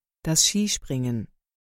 (das) Skispringen ： 発音に注意！ ”シー・シュプリンゲン”と読むよ！
Skispringen-2.mp3